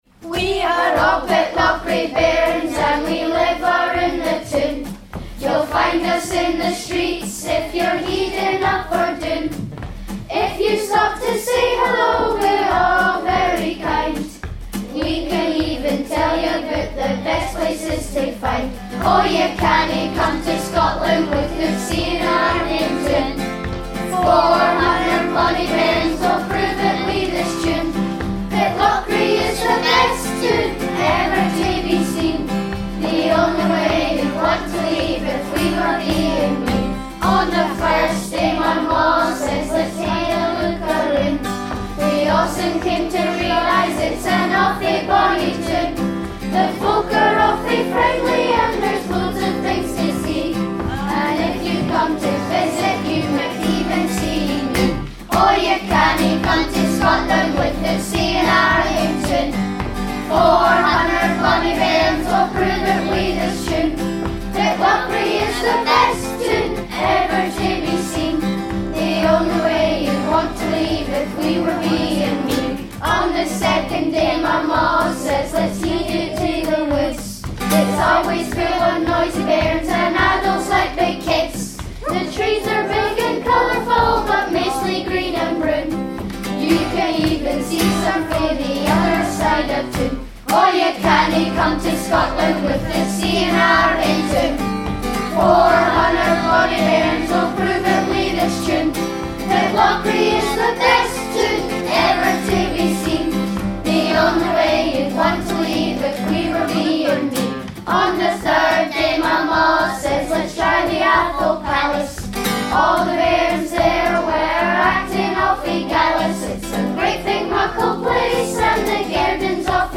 In November 2013 they had the opportunity to perform this song as part of Scotland Sings!